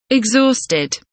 exhausted kelimesinin anlamı, resimli anlatımı ve sesli okunuşu